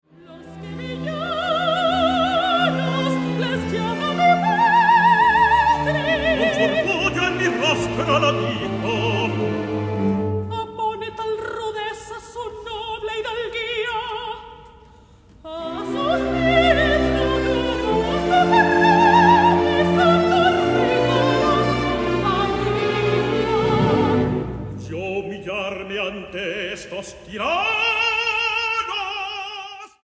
ópera en 4 actos